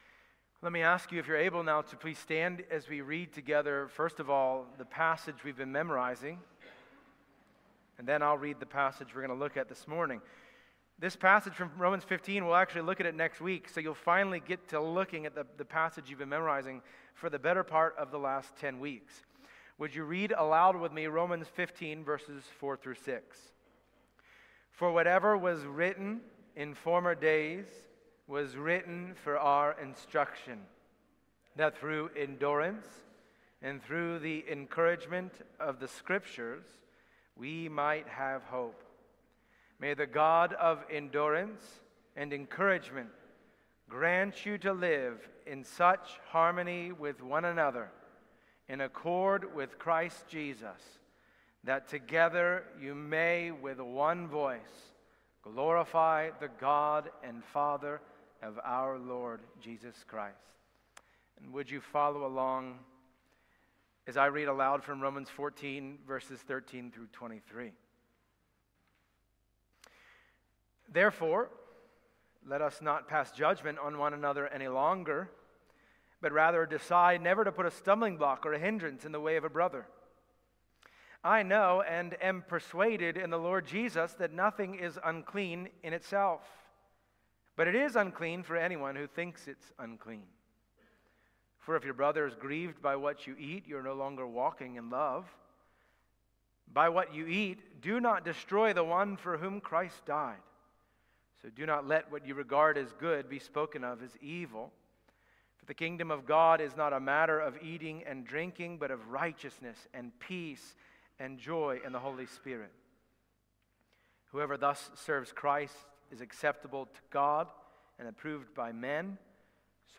Passage: Romans 14:13-23 Service Type: Sunday Morning